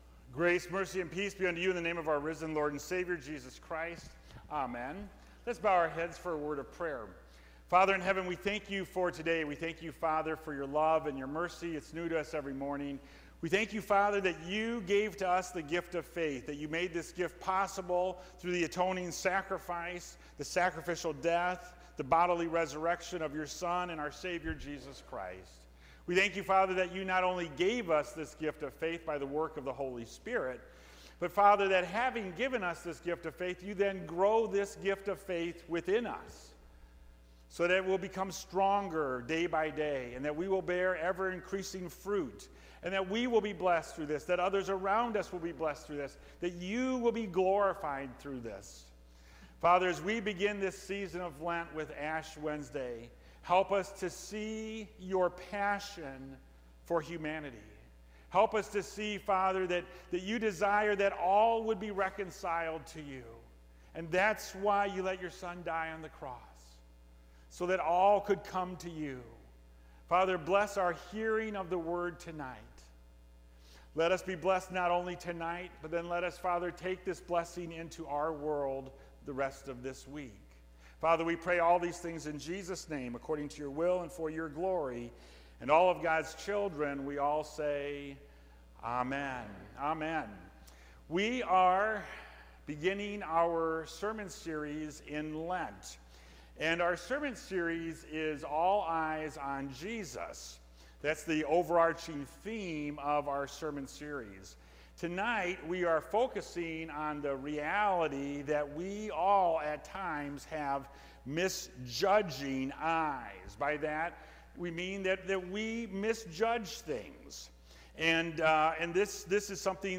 Ash Wednesday